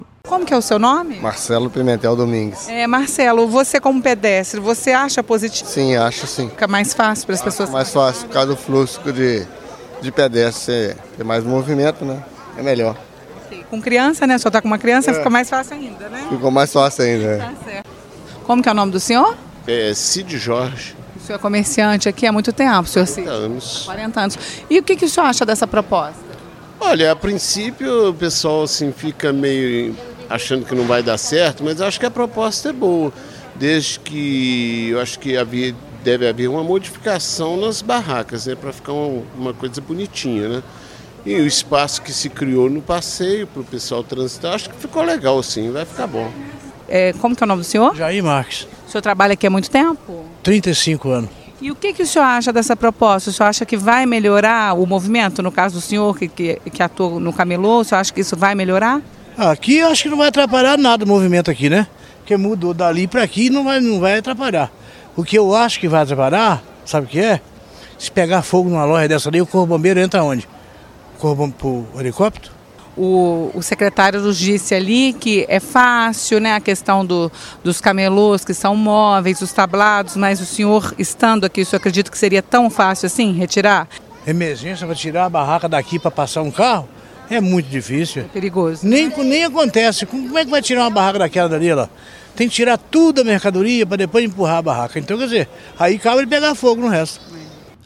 depoimentos da população